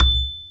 Xylophone C Major